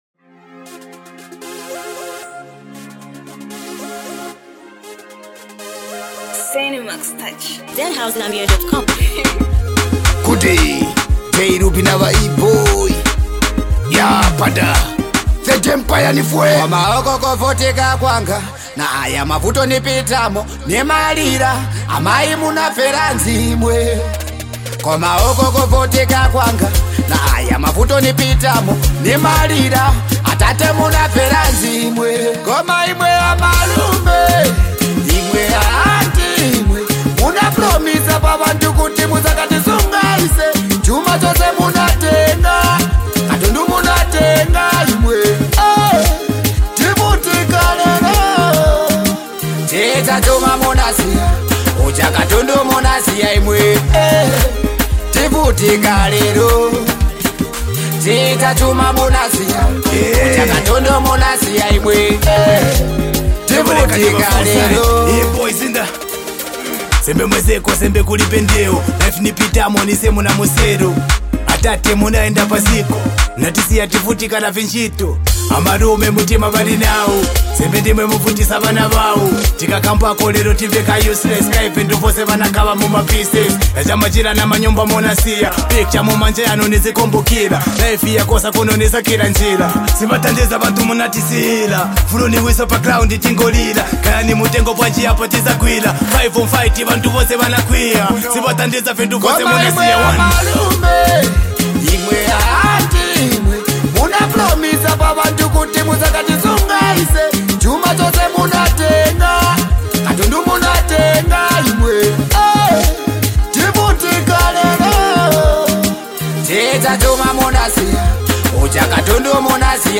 a vibrant and catchy banger